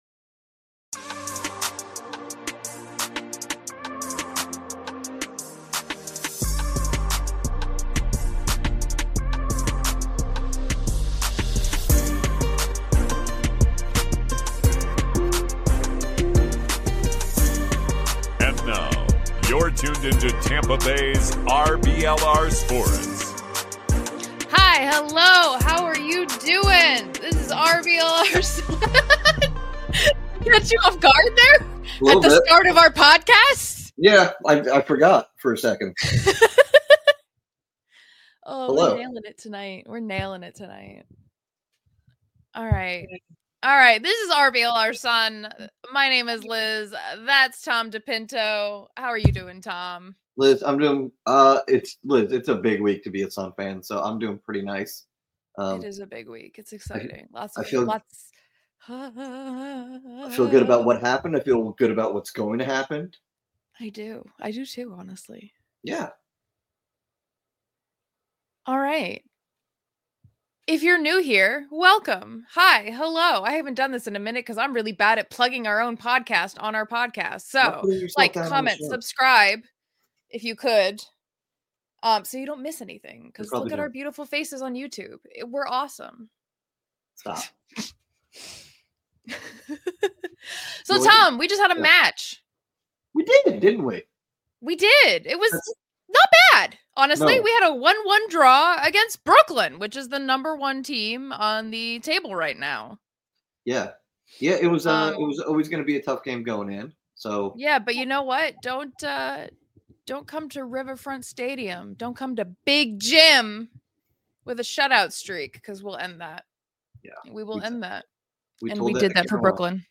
The Sun hosted Brooklyn for the next chapter in their journey, splitting the points after a 1-1 draw that was intense from the beginning. Jade Moore scored her first for the club, and honored us with an interview!